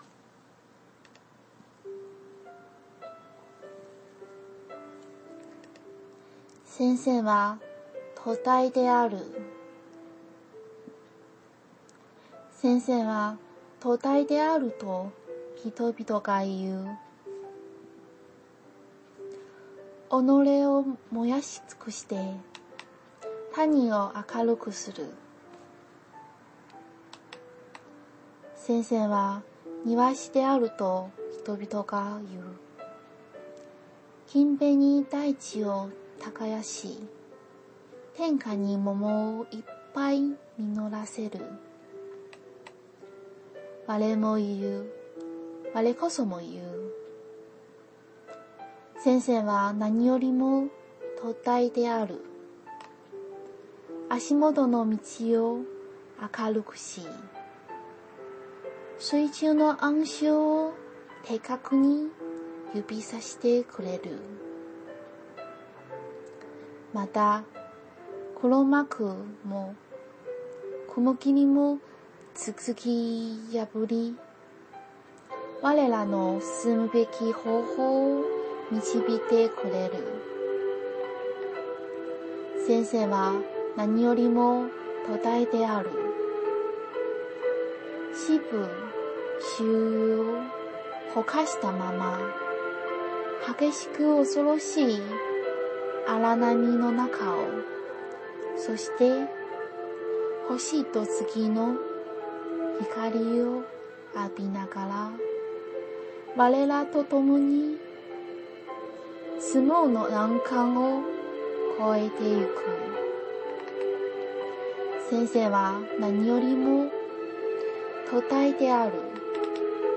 日语朗诵《老师是灯塔》